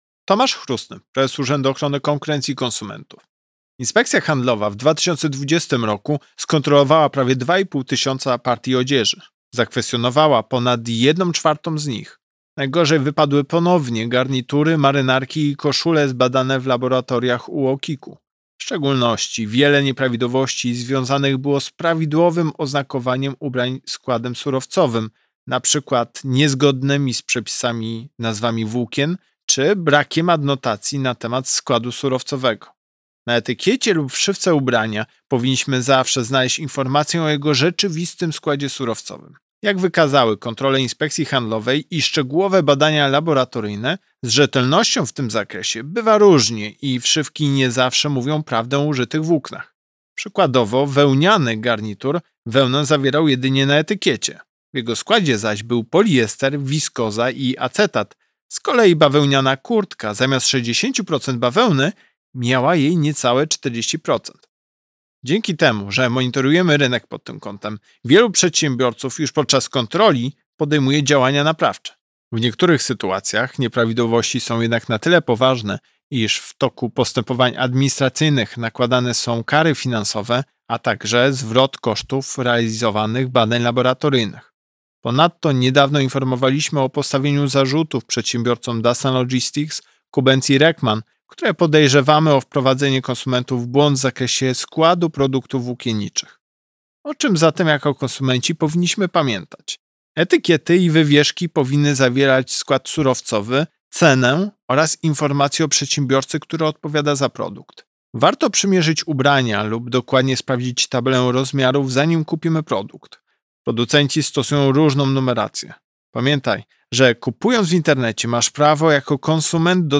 Pobierz wypowiedź Prezesa UOKiK Tomasza Chróstnego „Wełniany” garnitur wełnę zawierał jedynie na etykiecie – w jego składzie zaś był poliester, wiskoza i acetat, z kolei bawełniana kurtka zamiast 60 proc. bawełny, miała jej niecałe 40 proc.